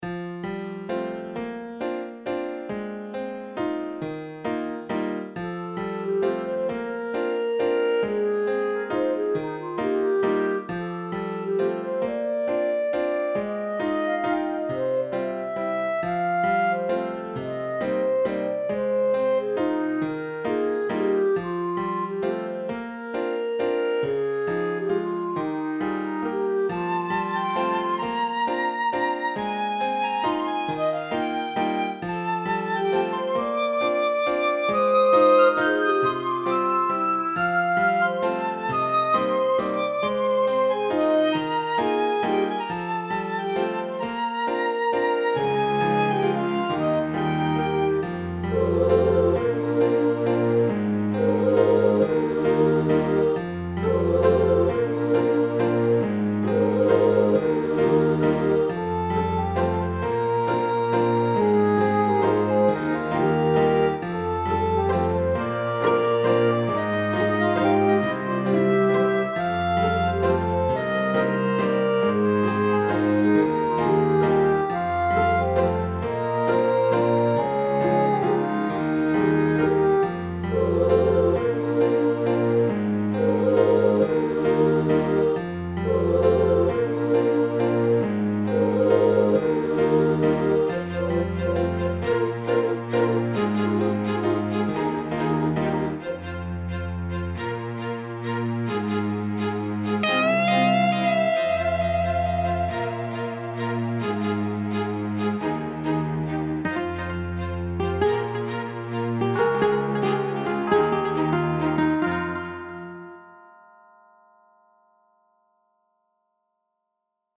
This song sounds like it should start playing as your characters walks into a new Inn looking for a weapon and armor upgrade, and maybe stay at an Inn for the night.